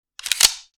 Part_Assembly_50.wav